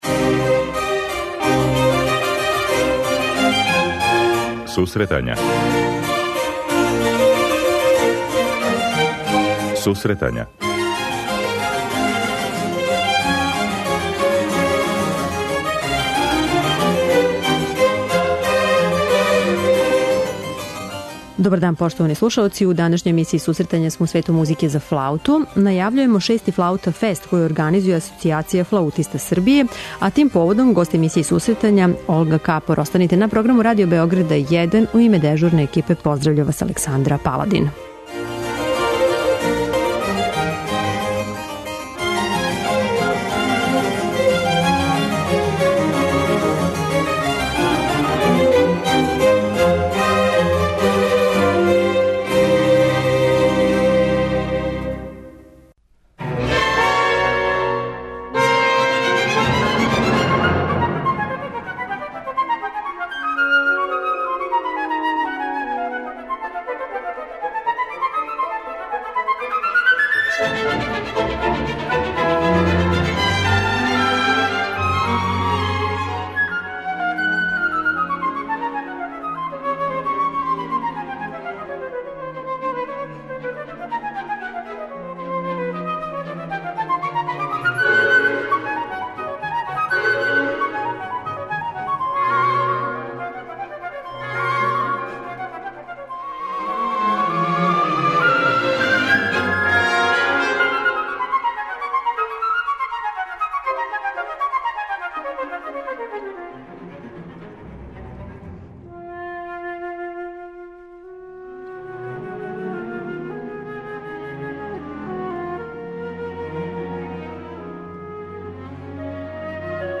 преузми : 26.03 MB Сусретања Autor: Музичка редакција Емисија за оне који воле уметничку музику.